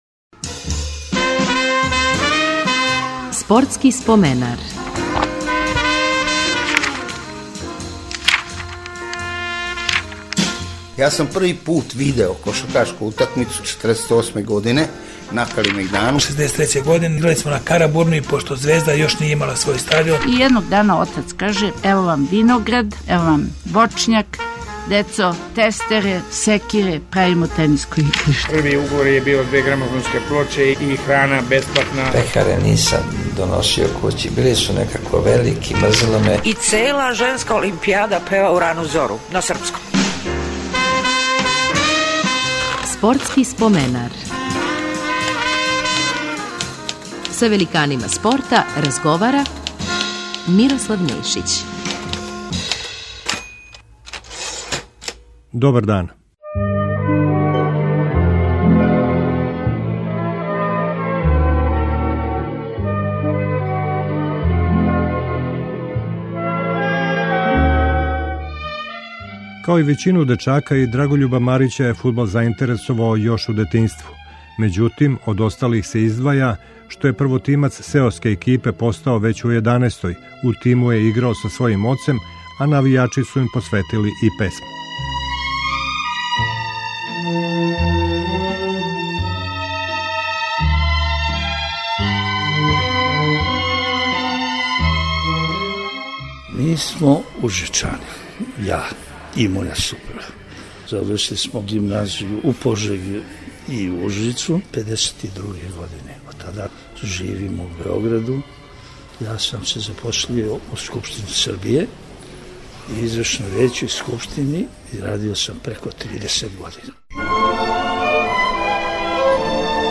Завршница емисје, уз коришћење материјала из нашег тонског архива, биће посвећена чувеном шаховском радио-мечу Југославија-Сједињене америчке државе одржаном 11. и 12. фебруара 1950.